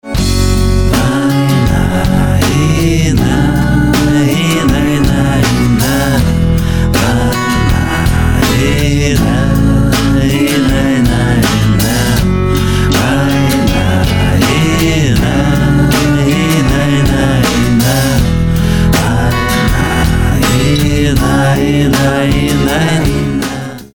• Качество: 320, Stereo
гитара
мужской вокал
душевные
русский рок
армейский шансон